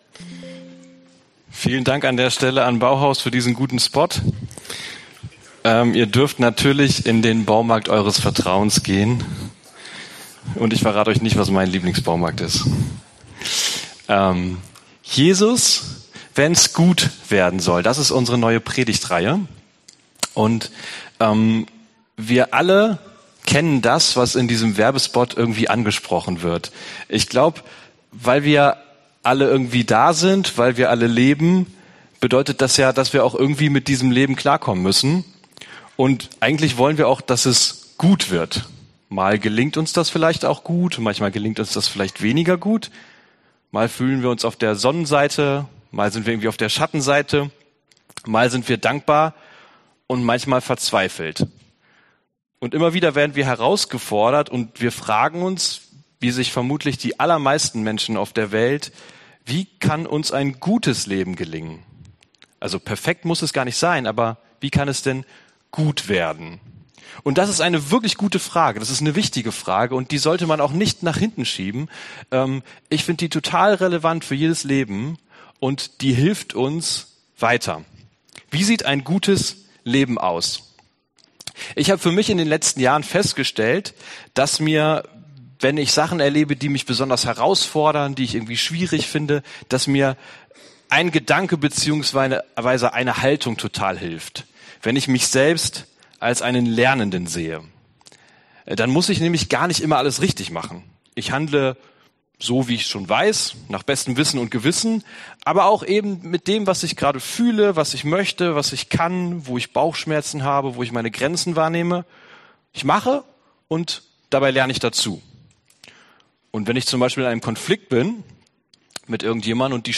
wenn's gut werden soll Dienstart: Predigt « Wie nah lass ich Jesus an mich heran?